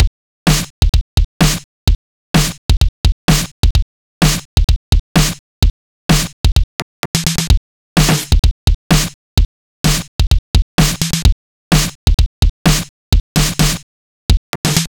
Session 11 - Kick _ Snare.wav